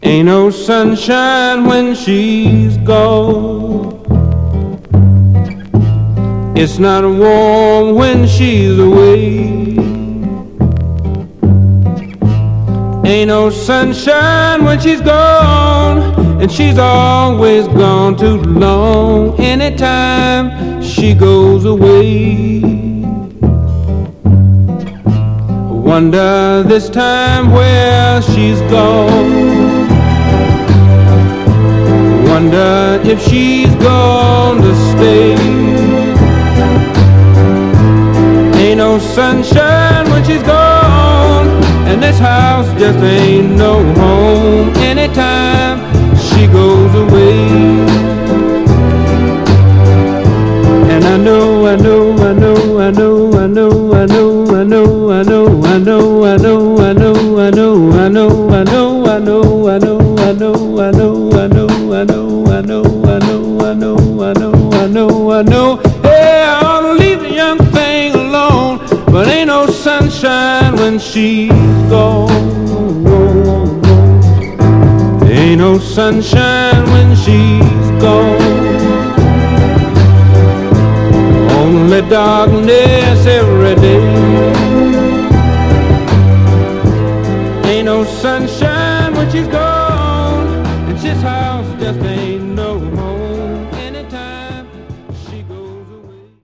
盤はA面に目立つ線キズあり、円周ノイズ出ます。
FORMAT 7"
※試聴音源は実際にお送りする商品から録音したものです※